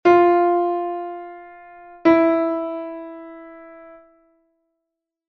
fa_mi_semitono.mp3